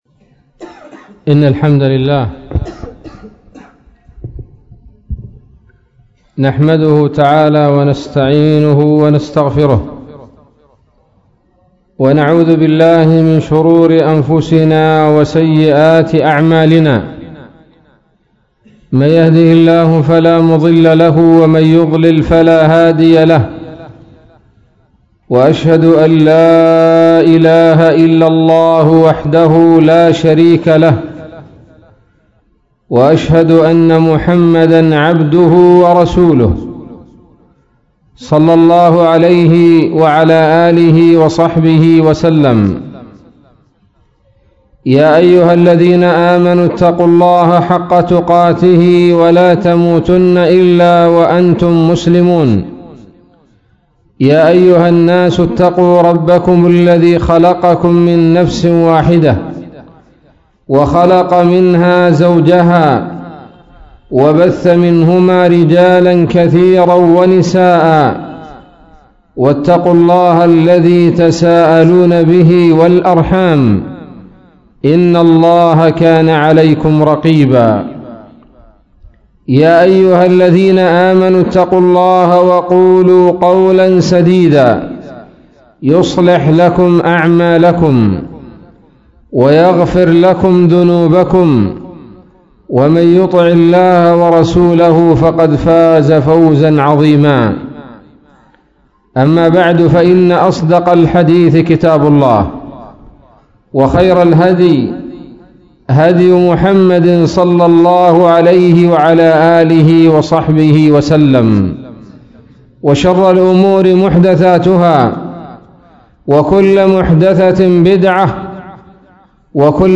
محاضرة قيمة بعنوان:(( اليمنيون ونصرة الدين
مسجد الخير - منطقة الخوخة